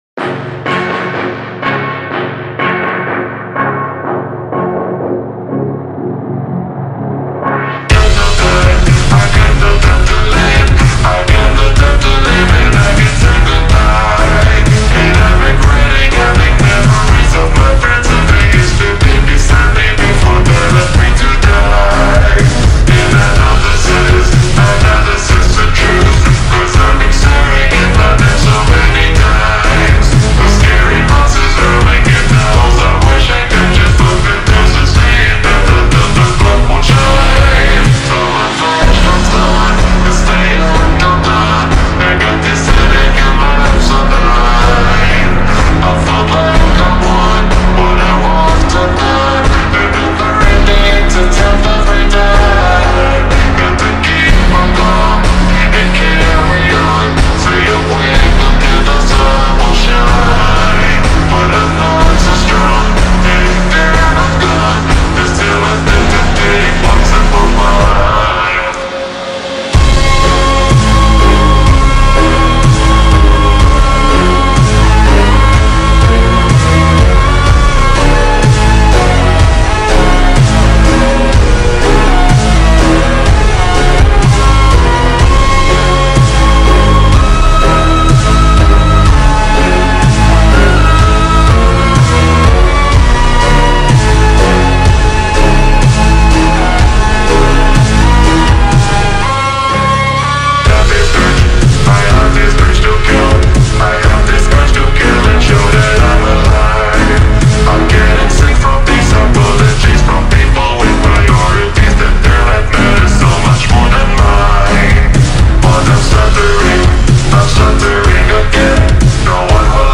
(Slowed-Reverb)